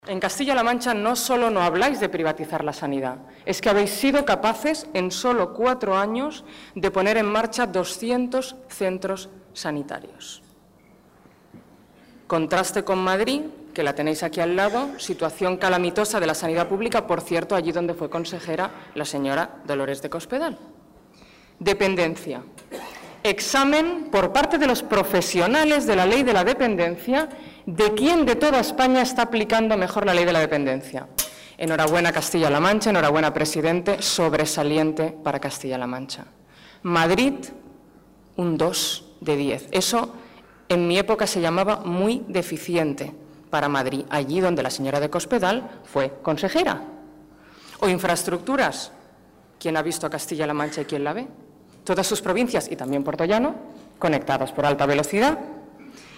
En el acto de celebración del centenario de la Agrupación de Puertollano